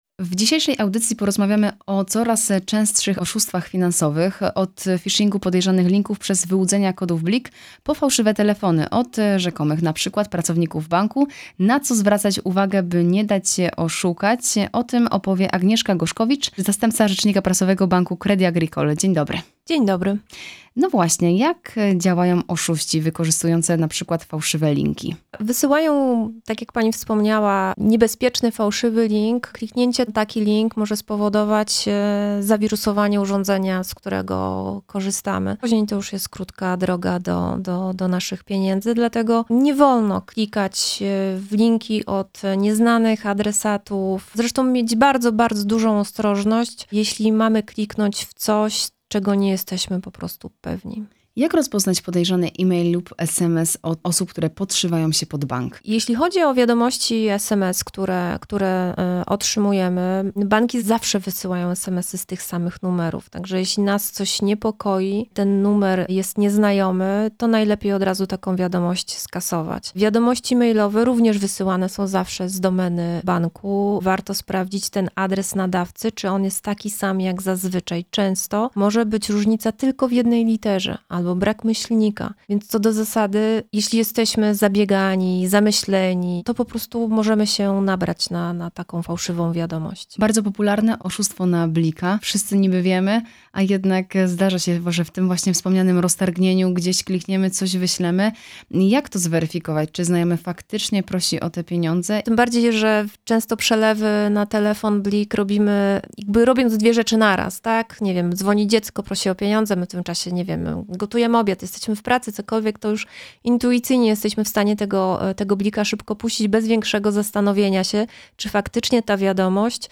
Credit-Agricole-rozmowa-o-oszustwach.mp3